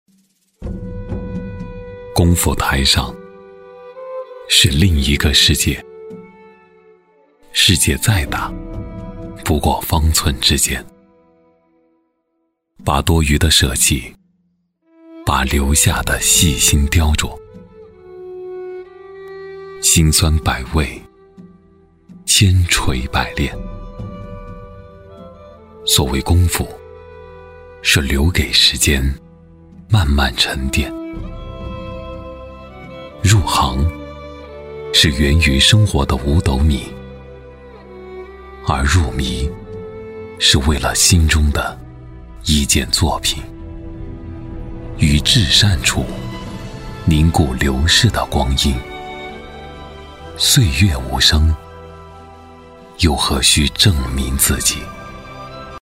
男139-微电影旁白【工匠 自然老成】-
男139-磁性沉稳 质感磁性
男139-微电影旁白【工匠 自然老成】-.mp3